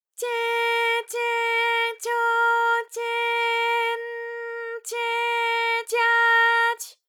ALYS-DB-001-JPN - First Japanese UTAU vocal library of ALYS.
tye_tye_tyo_tye_n_tye_tya_ty.wav